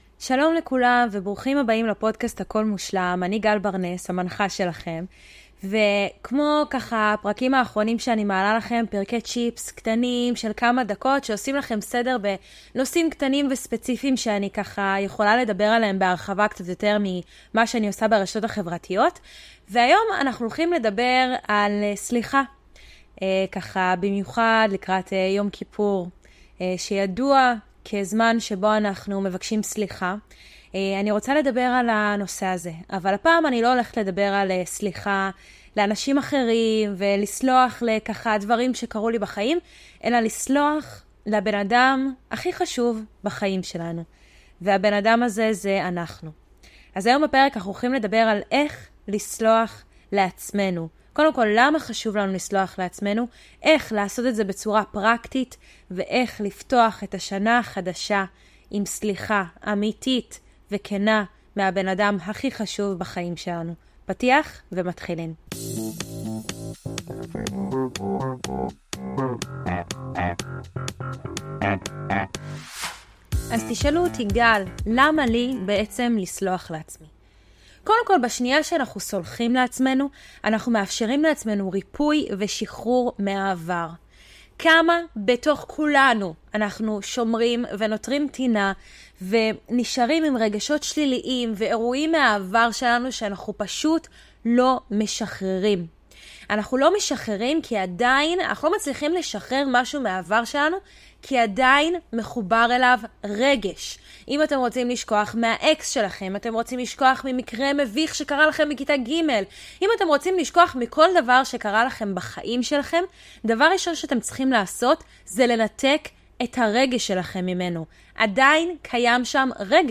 פרק סולו